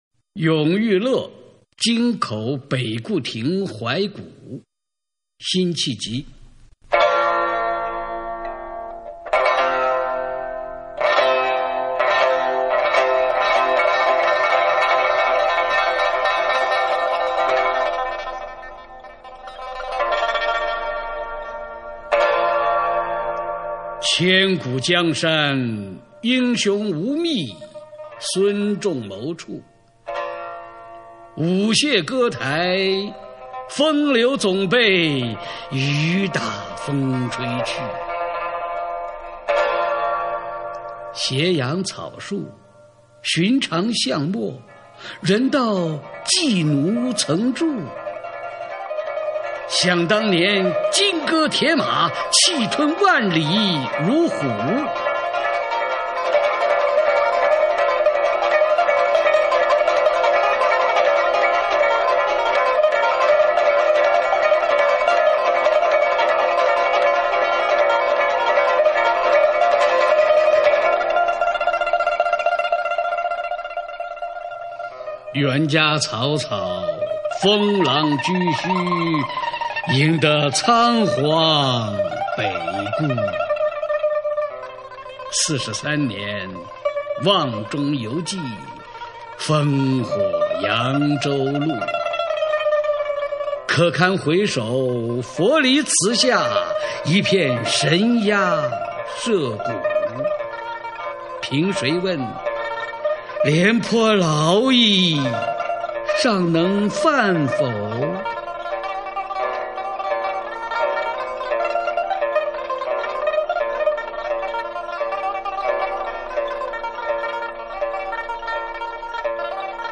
永遇乐·京口北固亭怀古 辛弃疾 经典朗诵欣赏群星璀璨：中国古诗词标准朗读（41首） 语文PLUS